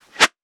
metahunt/weapon_bullet_flyby_25.wav at master
weapon_bullet_flyby_25.wav